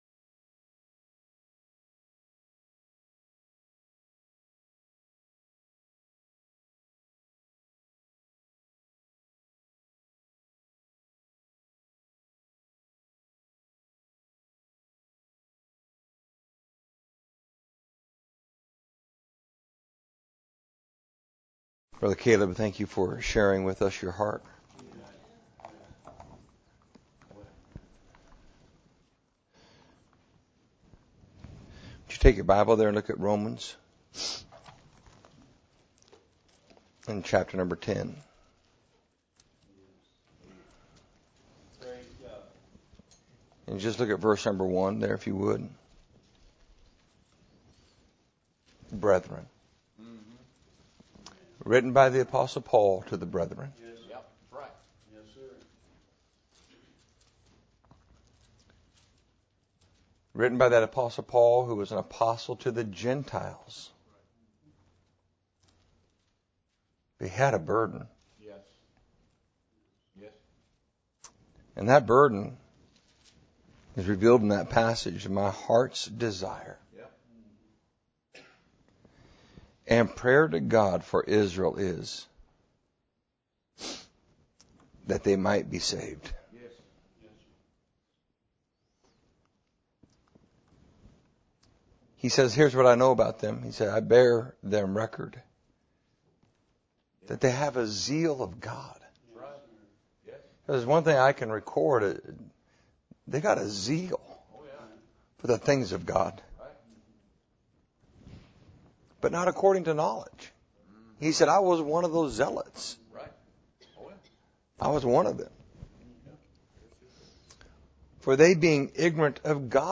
Missions Conference